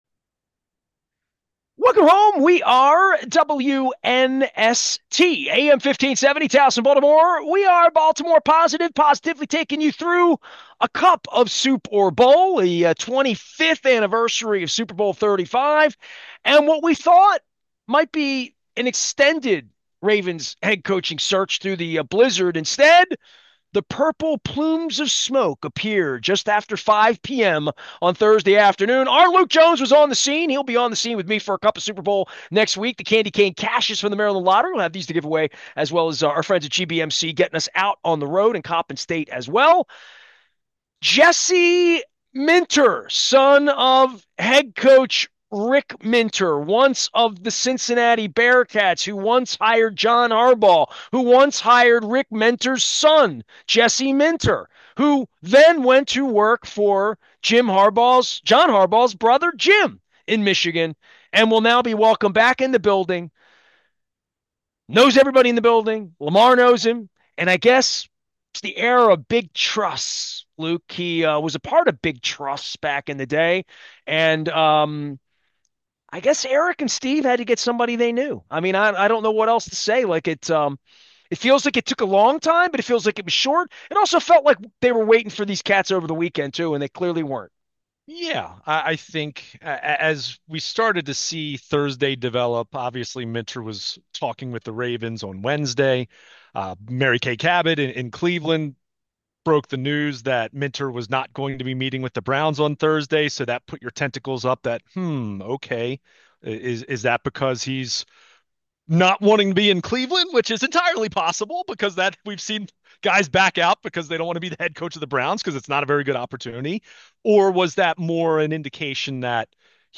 Intelligent conversation about all things Baltimore.